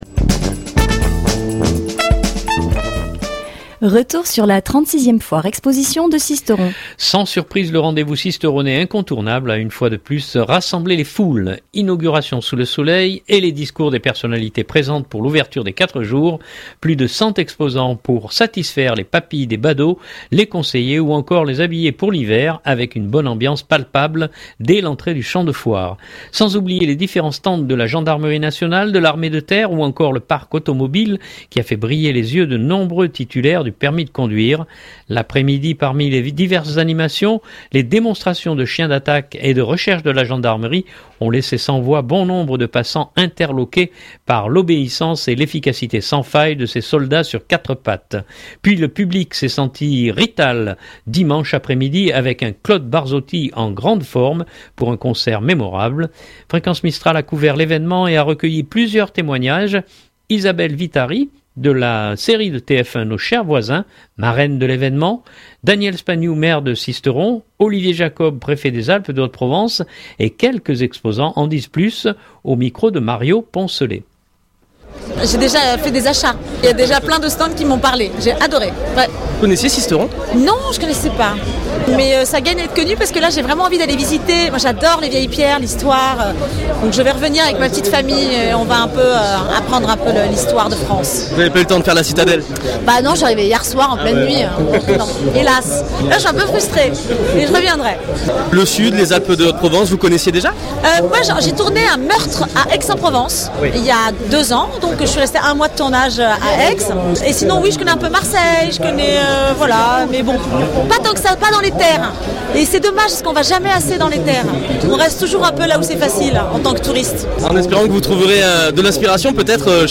Fréquence Mistral a couvert l’évènement et a recueilli plusieurs témoignages.